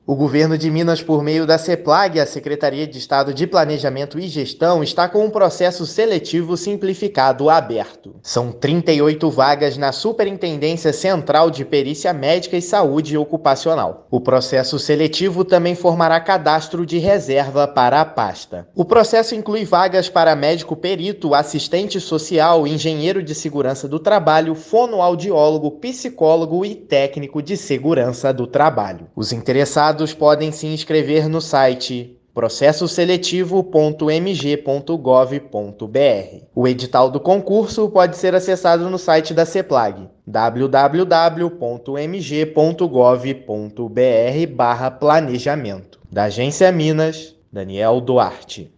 O processo conta com vagas de níveis técnico e superior para a capital e diversos municípios mineiros. Ouça a matéria de rádio: